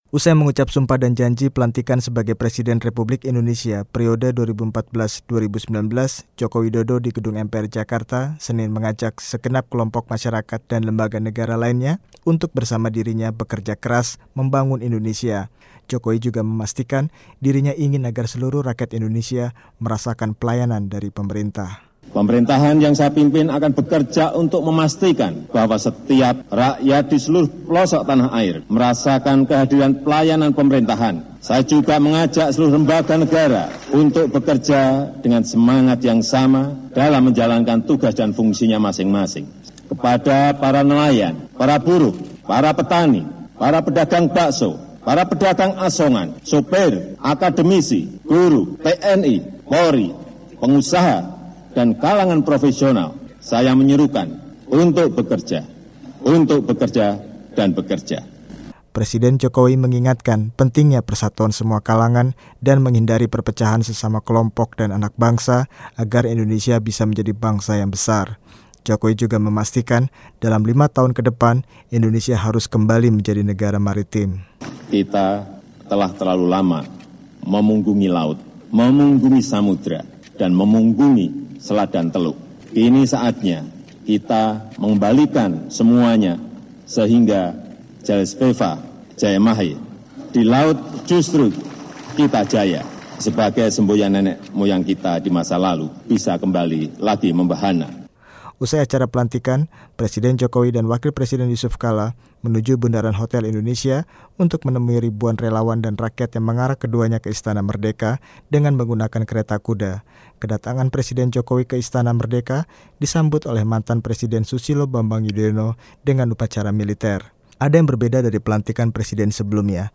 Dalam pidato pelantikan di gedung MPR, Presiden Joko Widodo memastikan rakyat Indonesia harus dapat merasakan pelayanan yang baik dari Pemerintah.